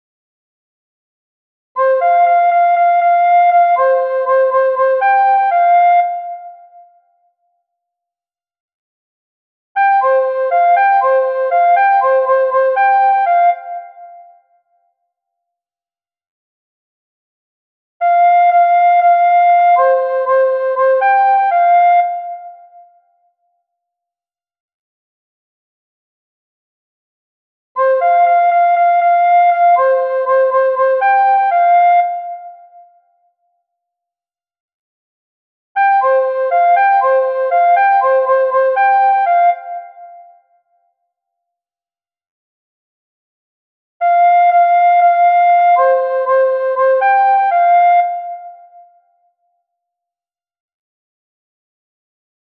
Esta é a música xunto coa partitura do baile, na que aparecen a melodía coa súa letra correspondente.